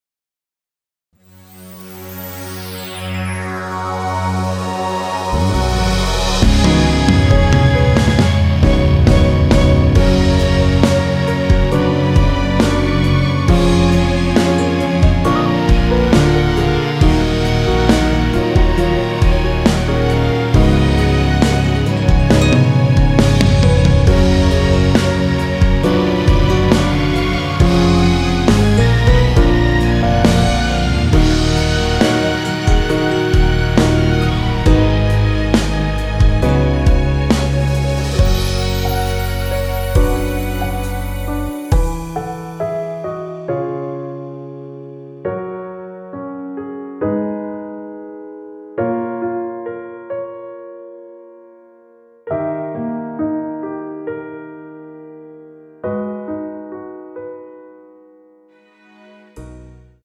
원키에서(+4)올린 MR입니다.
F#
앞부분30초, 뒷부분30초씩 편집해서 올려 드리고 있습니다.